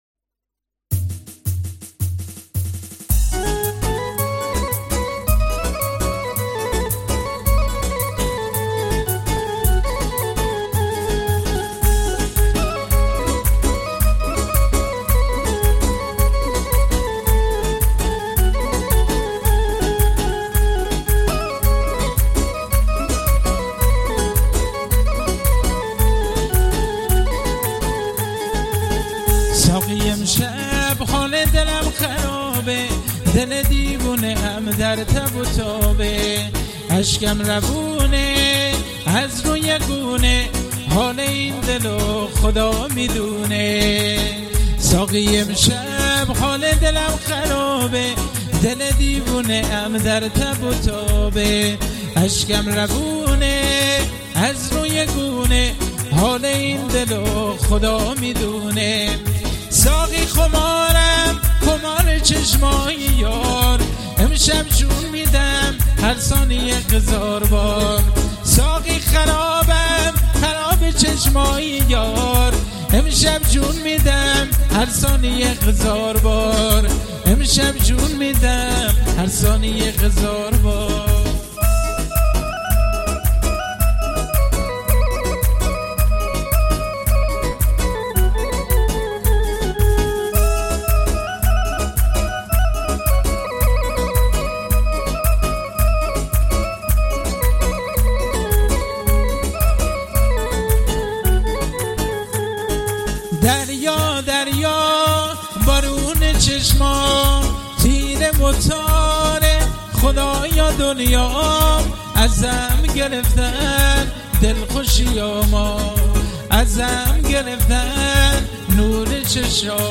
آهنگ کرمانجی